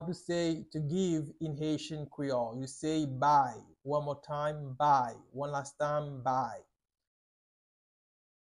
Pronunciation:
15.How-to-say-Give-in-Haitian-Creole-Bay-with-pronunciation.mp3